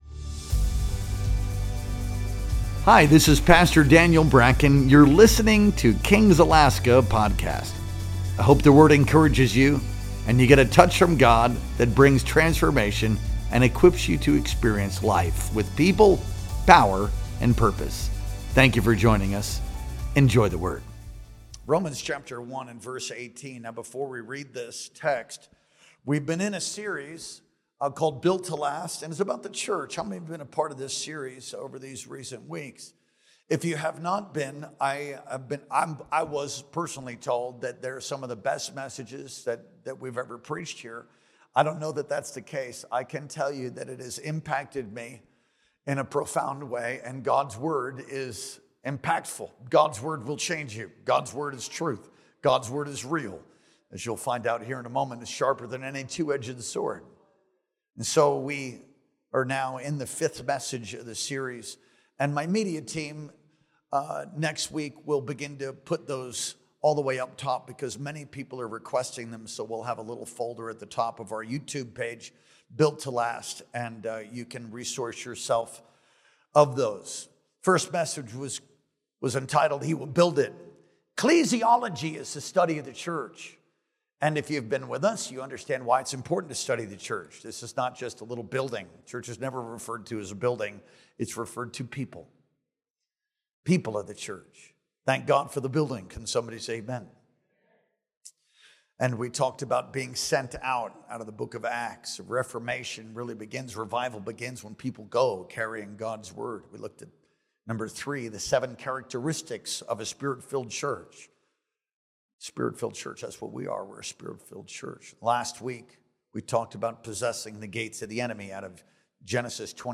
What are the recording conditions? Our Sunday Morning Worship Experience streamed live on September 21st, 2025.